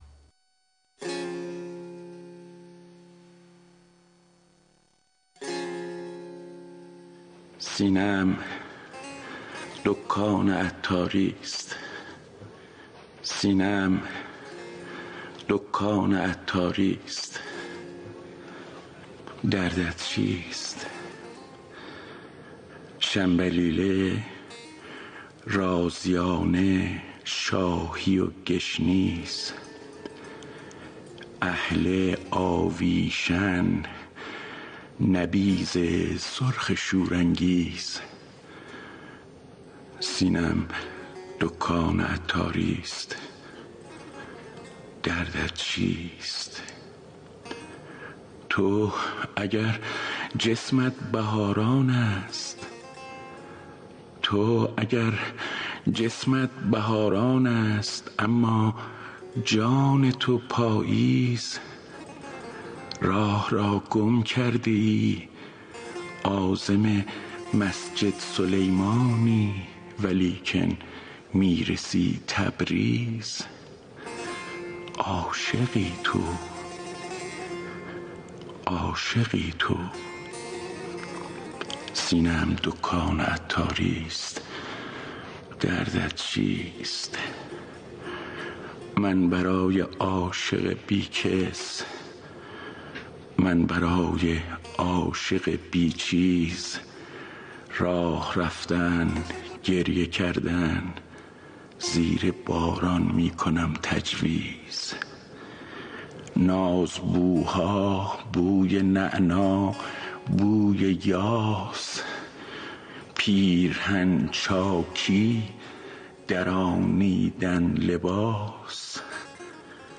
اطلاعات دکلمه
گوینده :   [محمد صالح‌علاء]